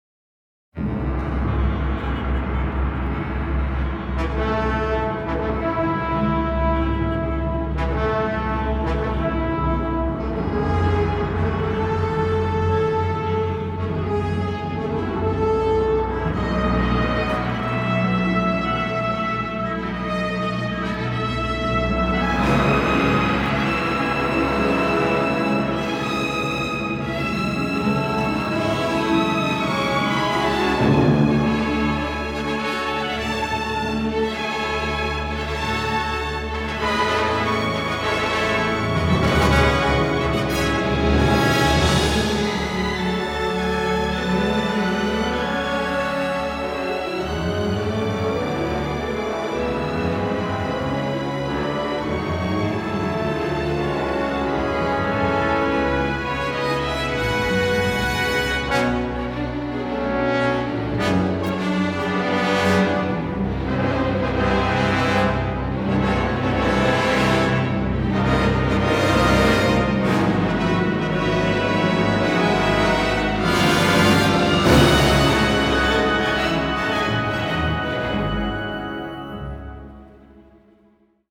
original classical compositions